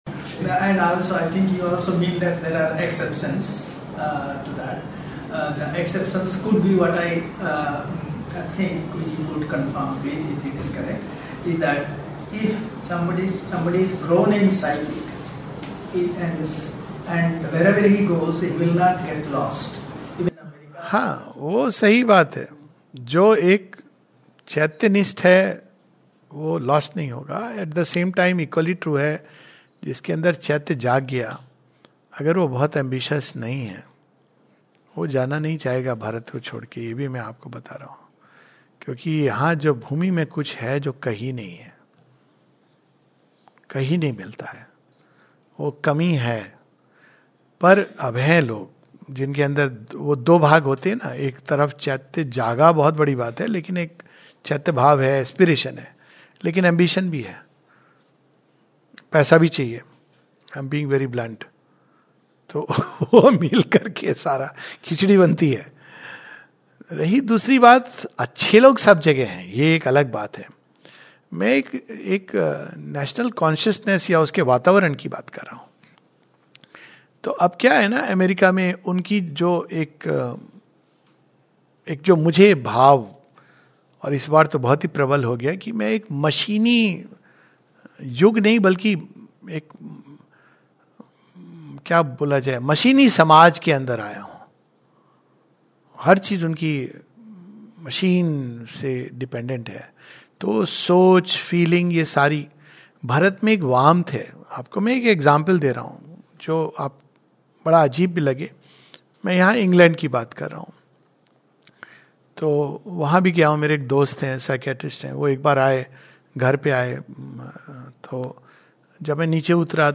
A brief reflection